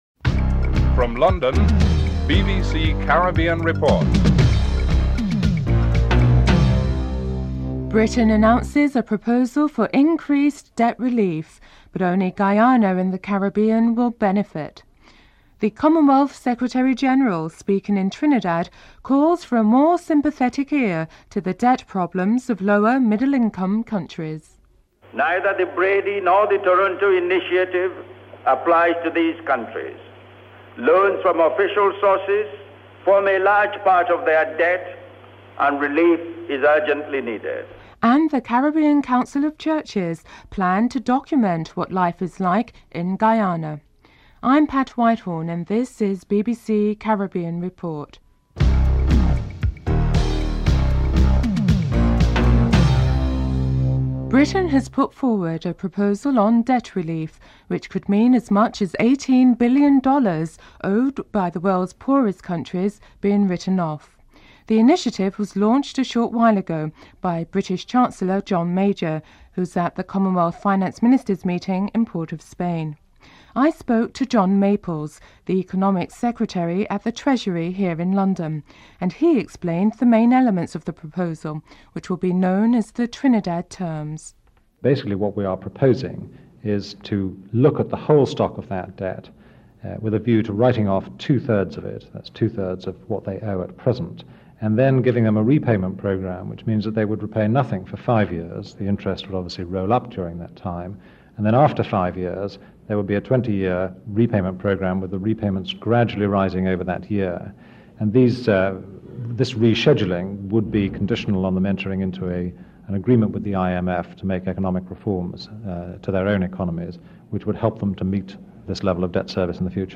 dc.description.tableofcontents4. Financial News.
dc.formatStereo 192 bit rate MP3;44,100 Mega bits;16 biten_US